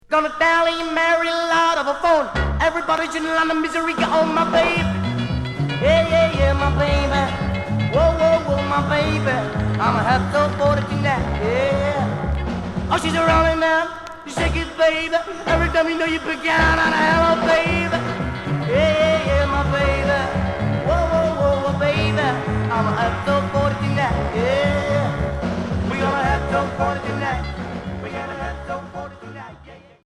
R'n'r